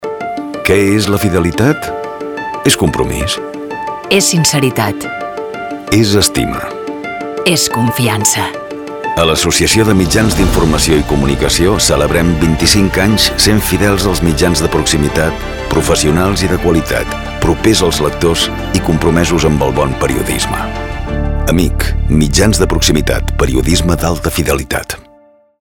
La imatge de la campanya dissenyada per l’agència de publicitat Pixel i que va comptar amb les veus dels actors Jordi Boixaderas i Sílvia Bel, es va centrar en la fidelitat, la proximitat i el compromís amb el bon periodisme que és el que representen els mitjans de proximitat locals i comarcals per a la seva comunitat de lectors.